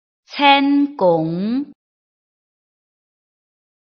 臺灣客語拼音學習網-客語聽讀拼-四縣腔-鼻尾韻
拼音查詢：【四縣腔】cen ~請點選不同聲調拼音聽聽看!(例字漢字部分屬參考性質)